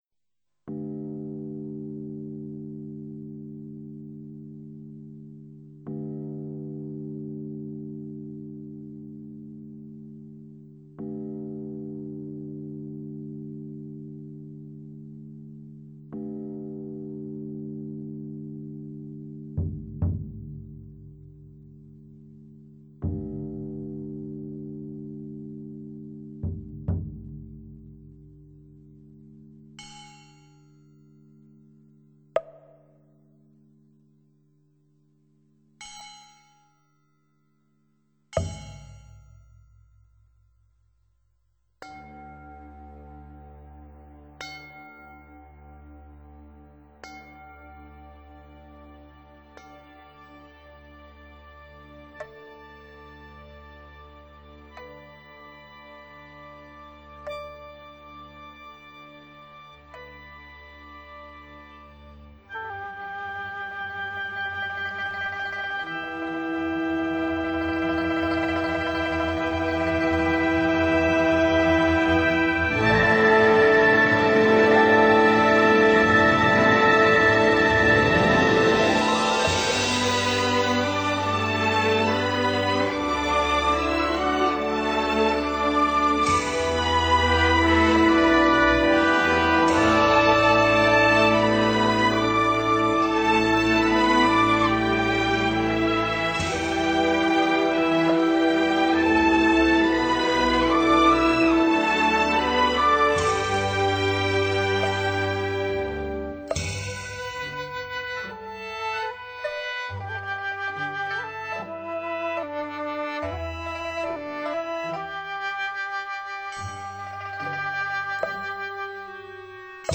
乐谱采自中国古书，曲风古朴、庄重、和雅，是传统佛教音乐的珍宝。